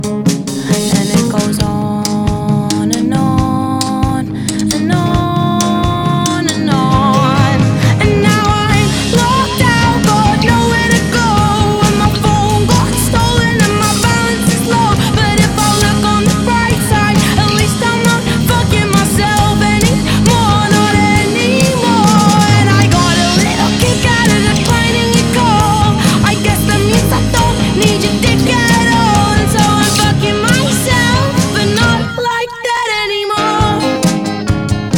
Alternative
Жанр: Альтернатива